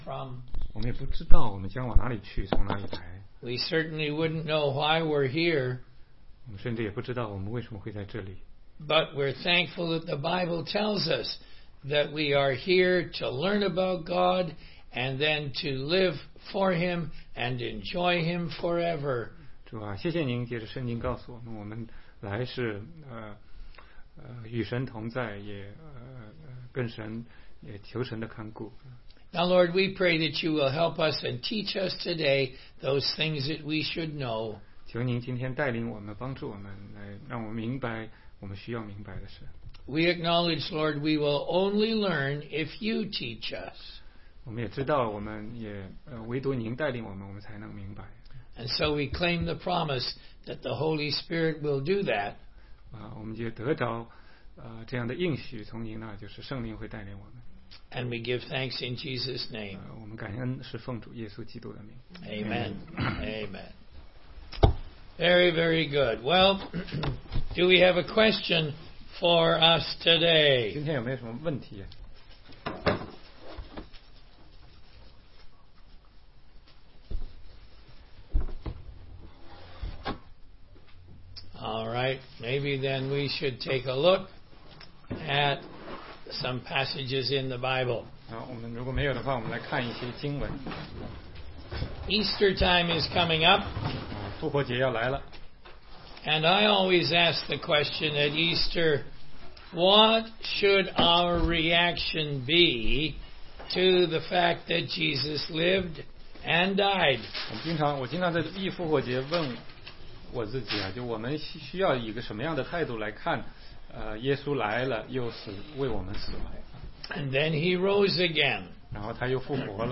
16街讲道录音 - 希律王的教训
答疑课程